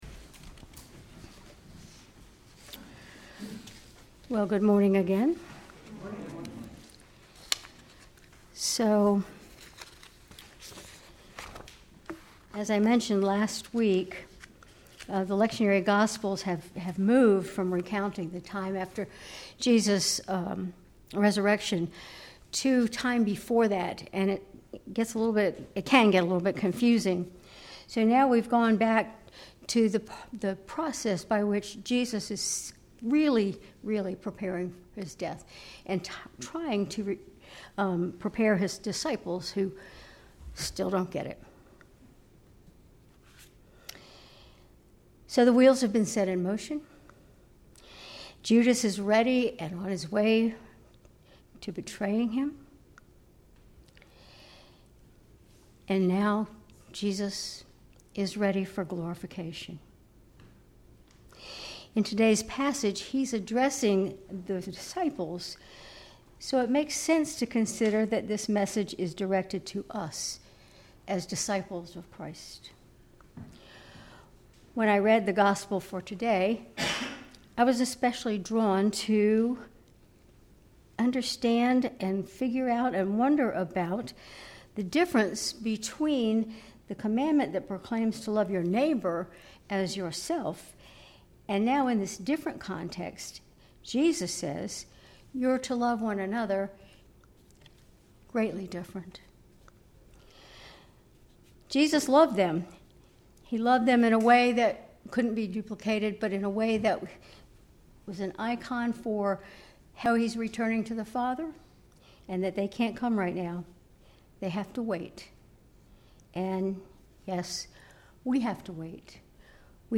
Sermon May 18, 2025